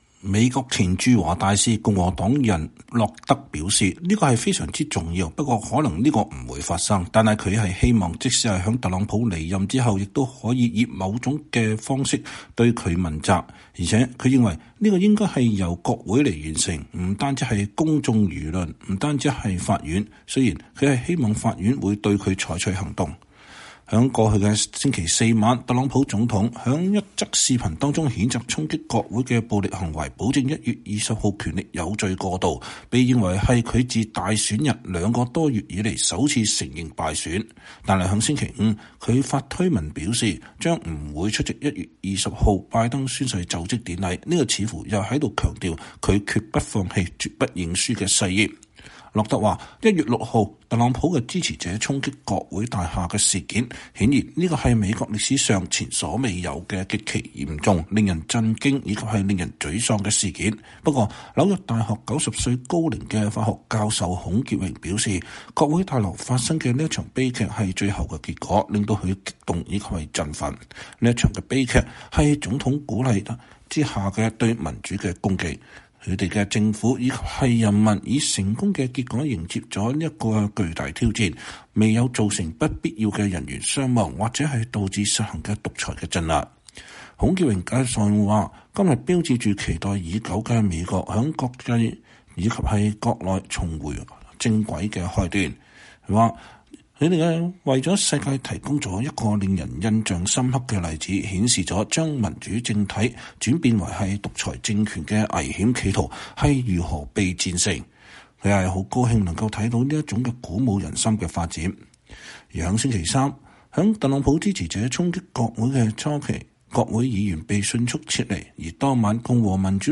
美國前駐華大使、共和黨人溫斯頓·洛德（Winston Lord）對美國之音說，“必須對特朗普進行問責”。